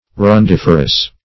Search Result for " arundiferous" : The Collaborative International Dictionary of English v.0.48: Arundiferous \Ar`un*dif"er*ous\, a. [L. arundifer; arundo reed + ferre to bear.] Producing reeds or canes.